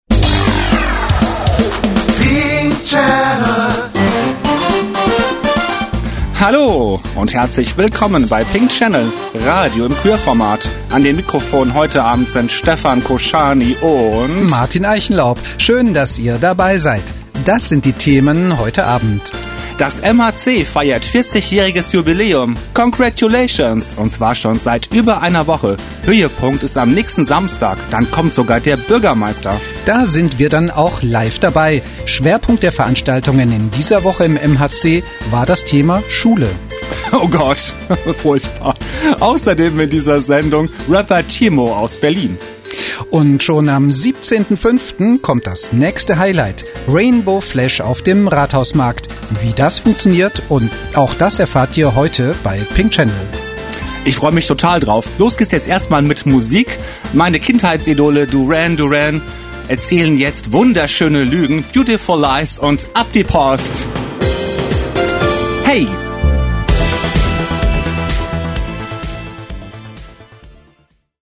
Radio im Queerformat.